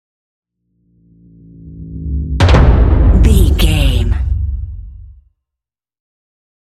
Dramatic whoosh to hit drum
Sound Effects
Atonal
dark
intense
tension
woosh to hit